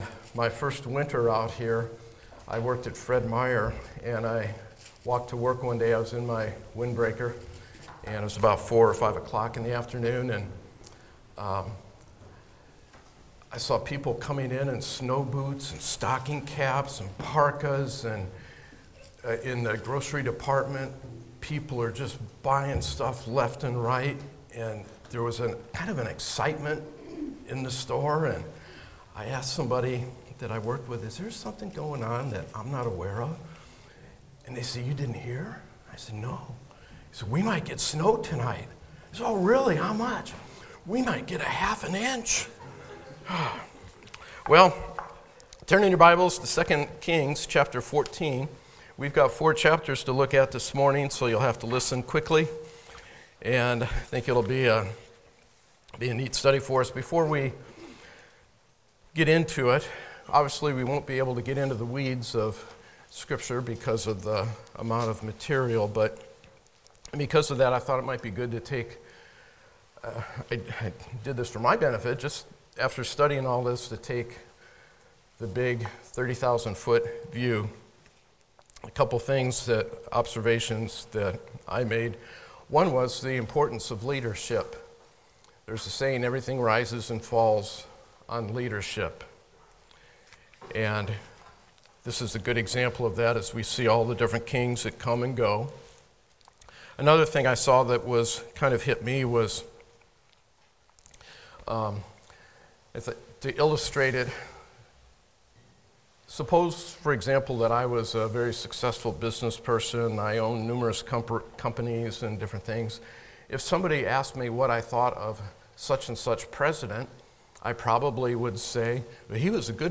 Passage: 2 Kings 14-17 Service Type: Sunday School